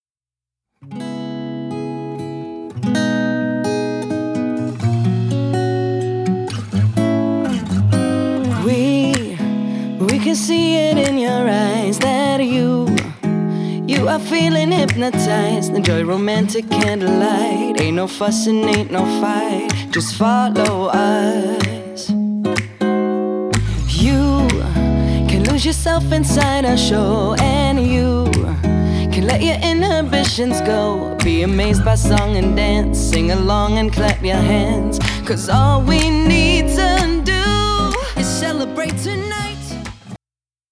Der unverfälschte Unplugged-Sound entführt Dich so,